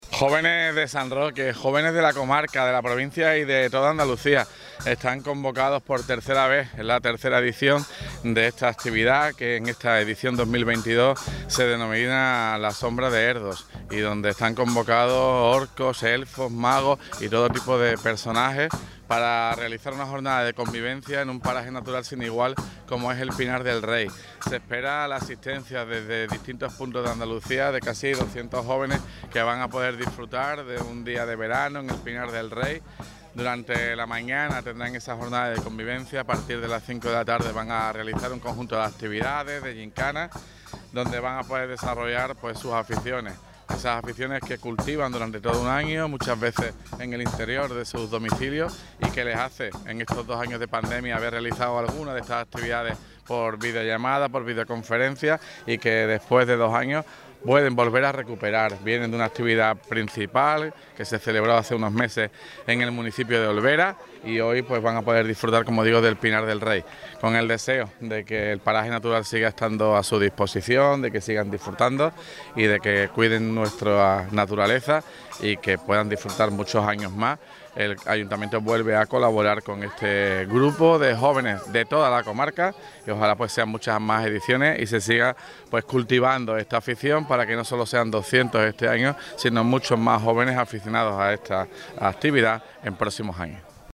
SOMBRAS_DE_ERDO_TOTAL_ALCALDE.mp3